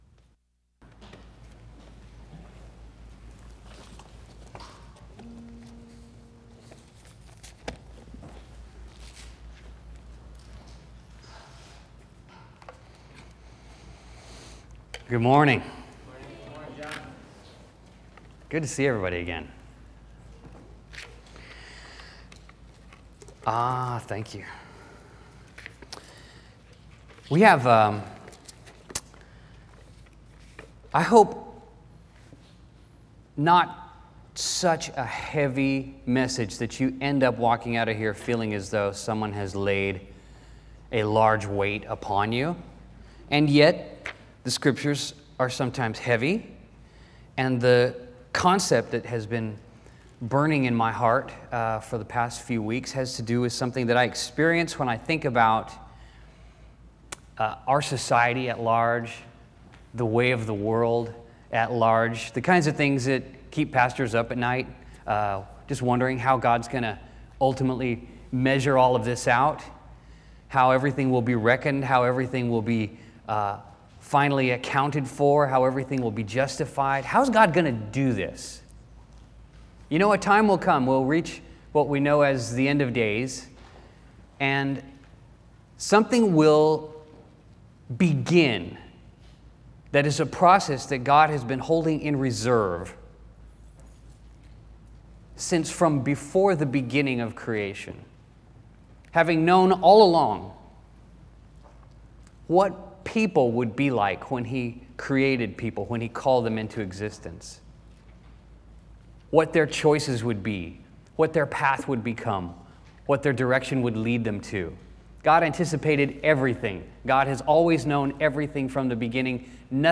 Bible Text: Luke 6:27-45, Deuteronomy 19 | Preacher